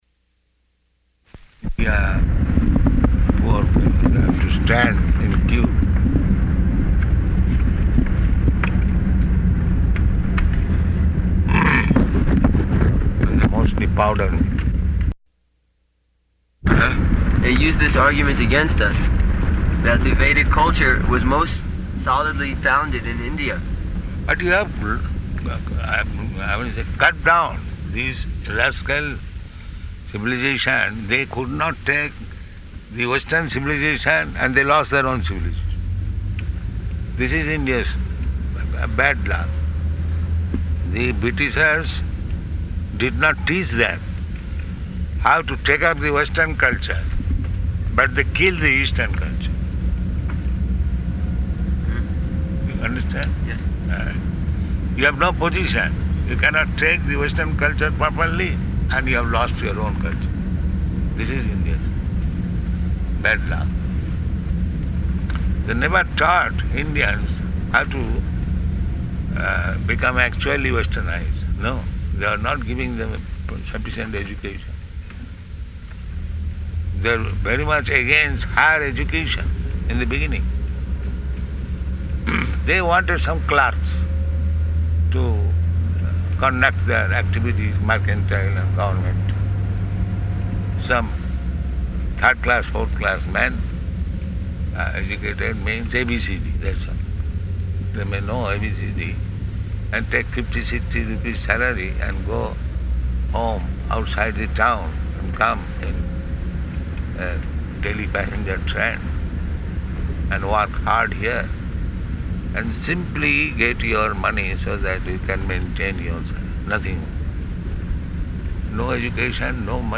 Morning Walk --:-- --:-- Type: Walk Dated: October 18th 1975 Location: Johannesburg Audio file: 751018MW.JOH.mp3 [in car] Prabhupāda: ...here poor people have to stand in queue.